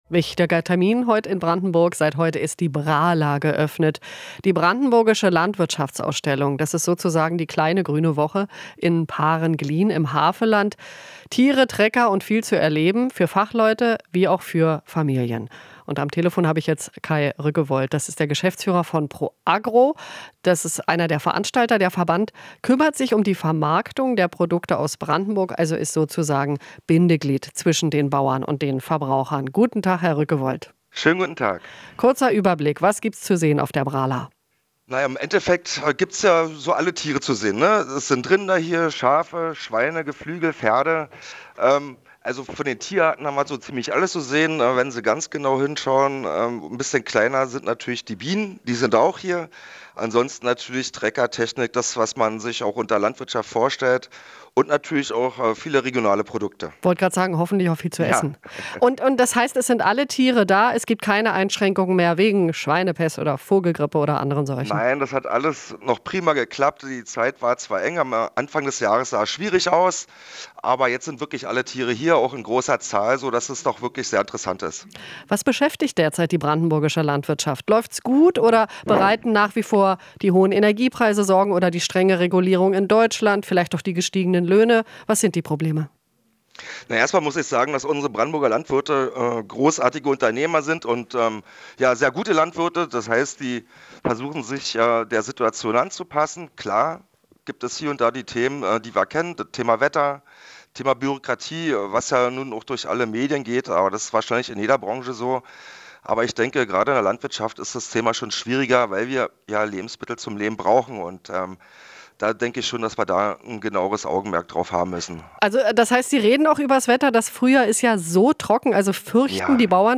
Interview - Tiere und Trecker: Brandenburger Landwirtschaftsausstellung eröffnet